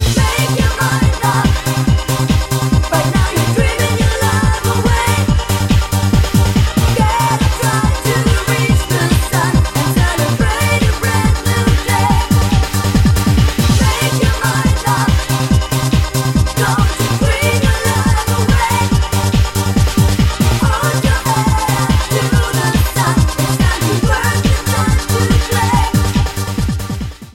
• Качество: 128, Stereo
евродэнс